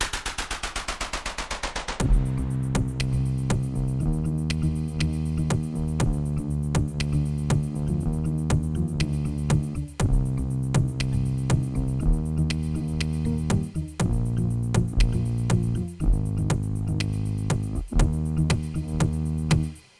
עזרה בווקאלי
אגב, לבסוף הסתדרתי בעניין, פשוט הקלטתי את עצמי מזמזם את תפקיד הבס, הנמכתי באוקטבות והעמסתי באפקטים והנה התפקיד בס הסופי בתוספת תופים ווקאלים וערוץ “טום טום”